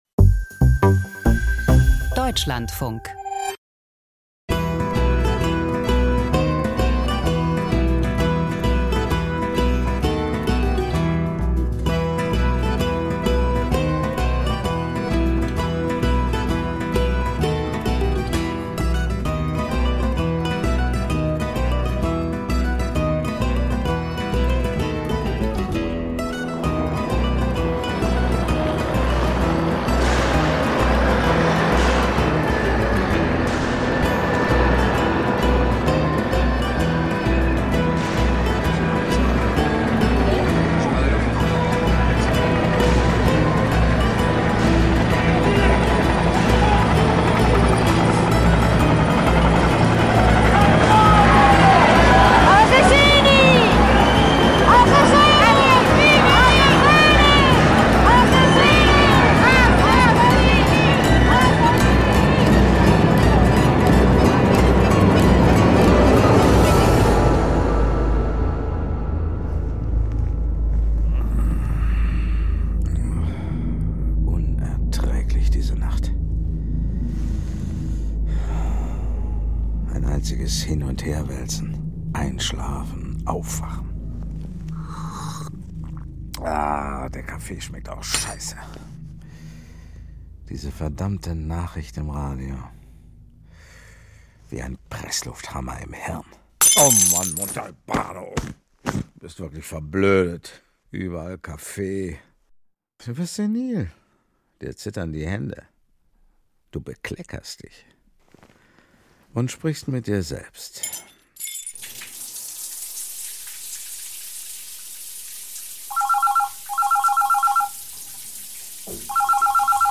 Toter Mann – Krimi-Hörspiel von Andrea Camilleri